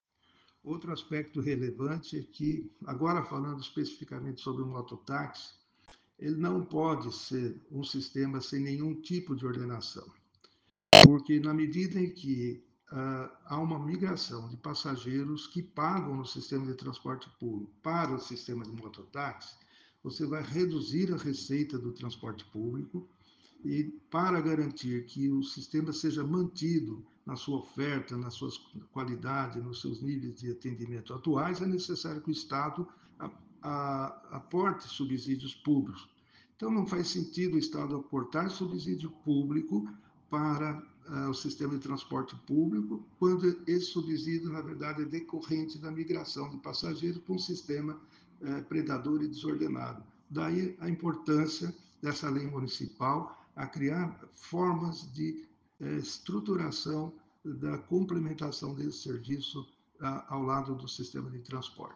ENTREVISTA: